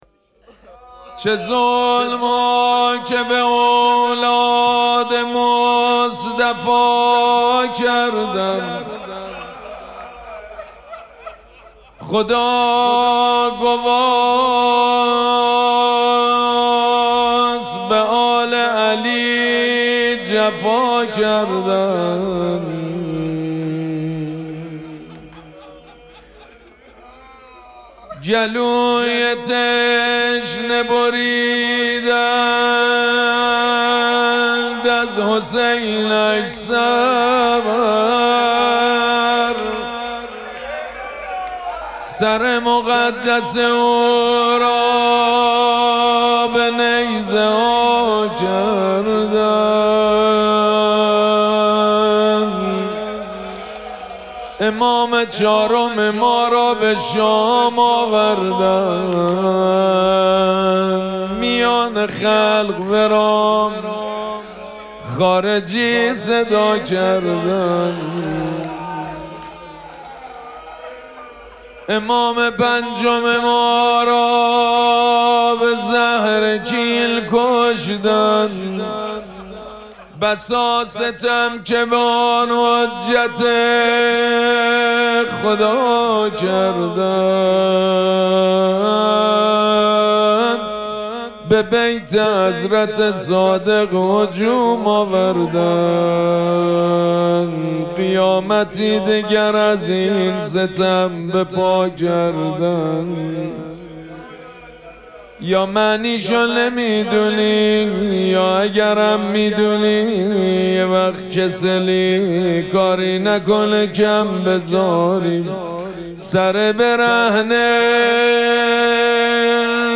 مراسم عزاداری شهادت امام صادق علیه‌السّلام
روضه
مداح
حاج سید مجید بنی فاطمه